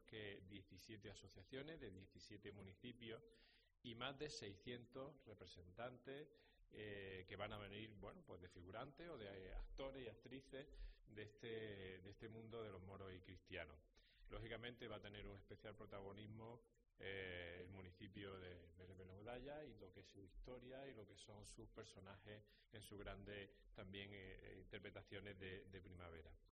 Fernando Egea, delegado de Cultura